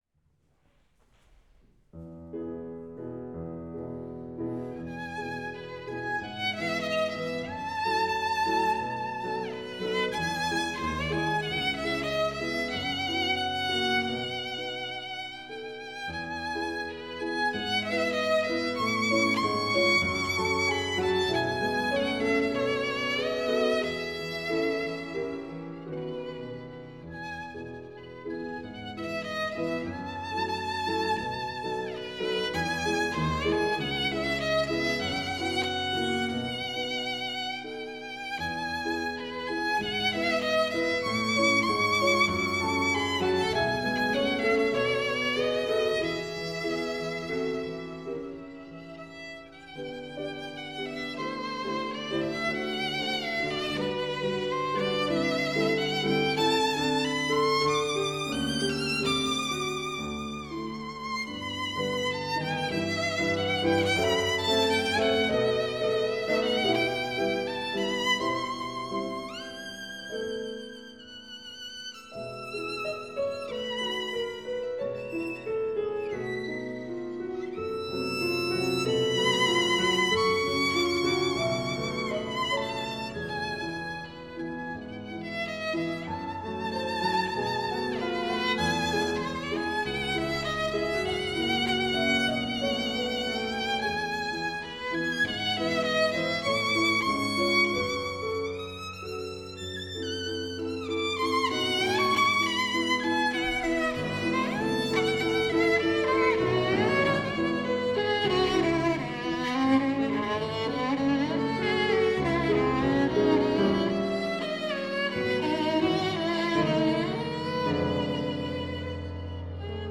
Geige A:
Geige A: Stradivari „Ludwig“ von 1724
Violine_Stradivari.mp3